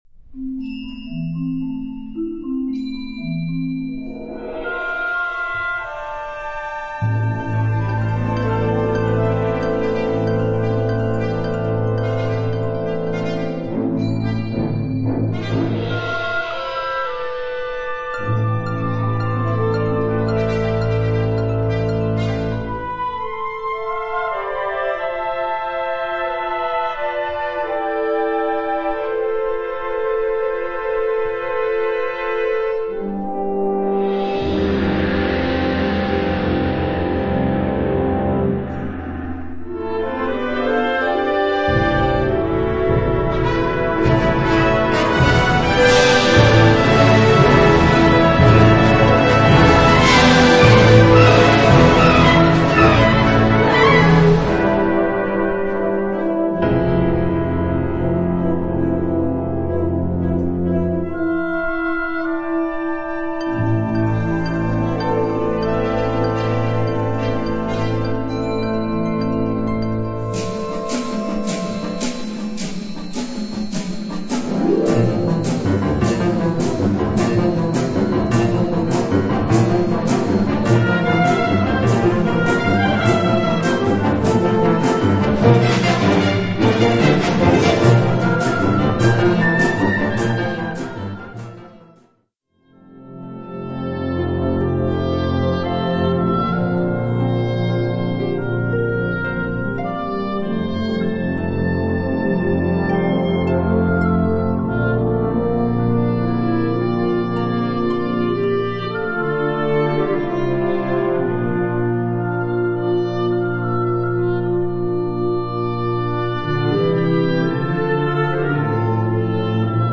Subcategorie Hedendaagse blaasmuziek (1945-heden)
Bezetting Ha (harmonieorkest); CB (Concert Band)